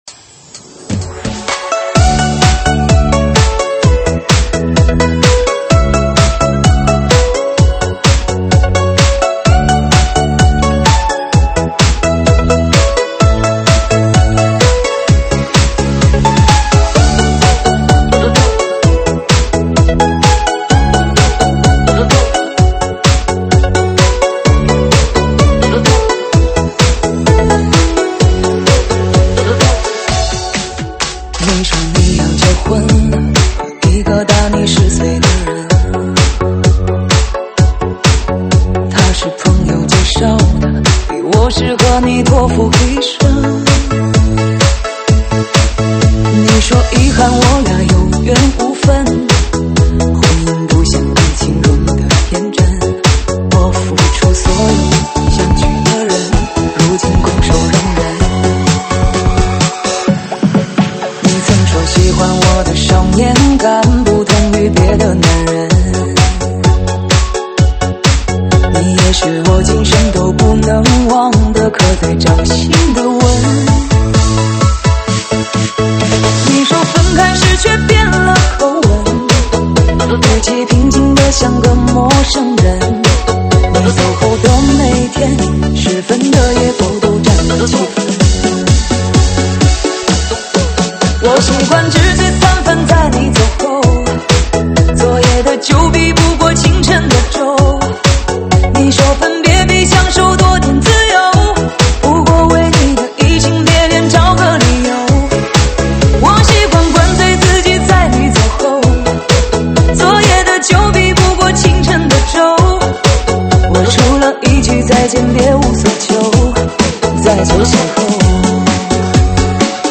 舞曲类别：车载大碟